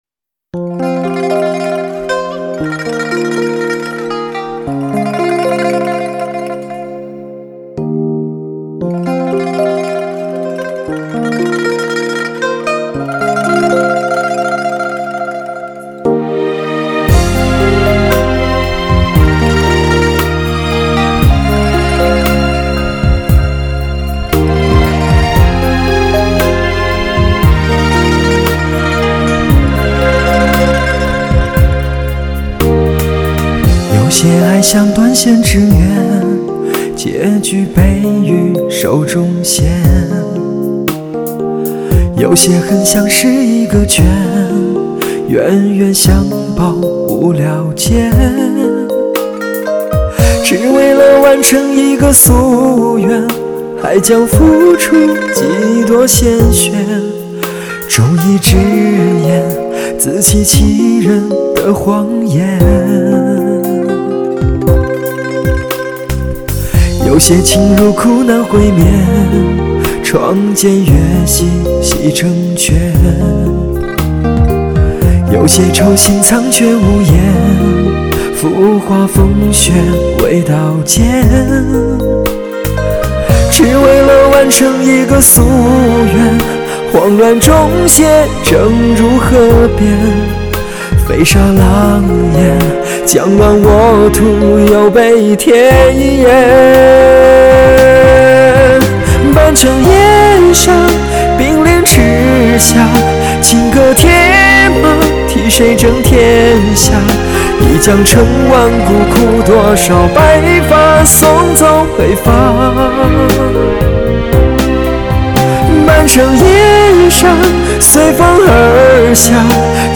乐坛首席情歌男声至臻演绎 浓缩歌者多年心路历程
疗伤情歌十载集大成之作 音响美学典范 人声发烧极致
50万美金的大提琴倾情伴奏